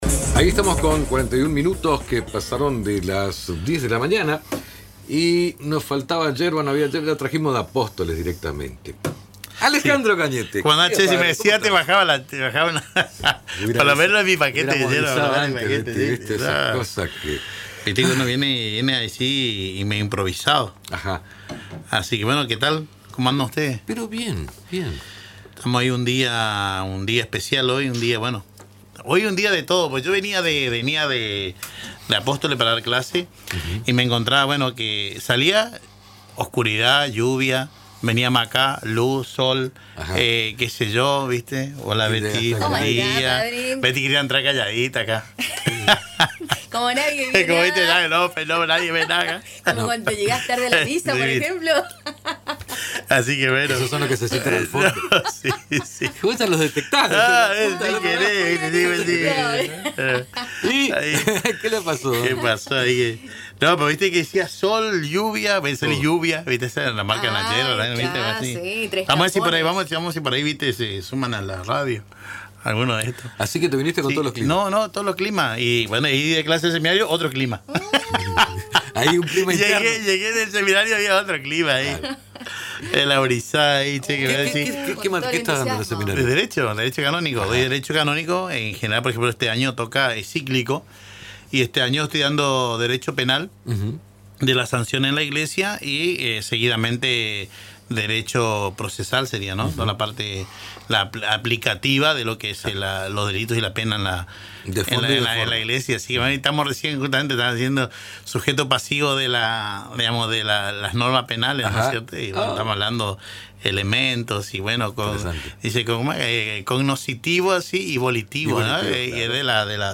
Durante la entrevista, compartió reflexiones sobre la vida pastoral, la formación sacerdotal y el legado de Francisco.